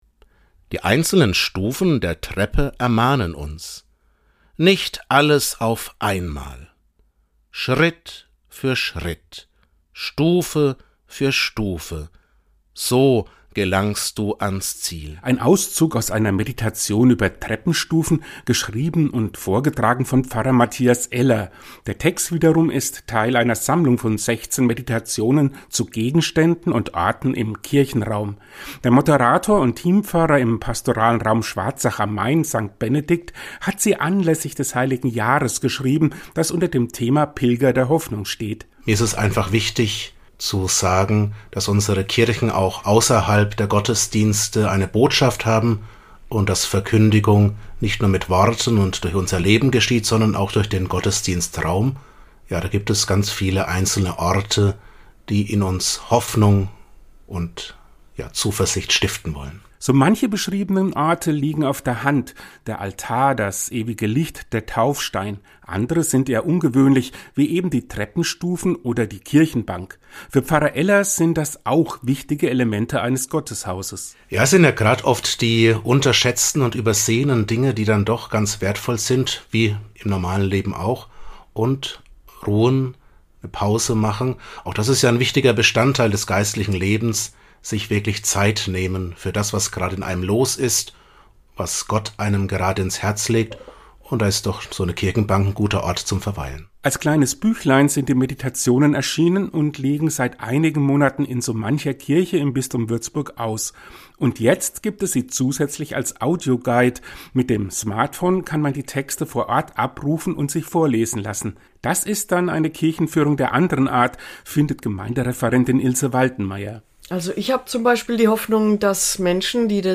und hat sich einen kurzen Auszug aus dem Buch vorlesen lassen.